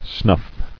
[snuff]